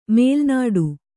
♪ mēlnāḍu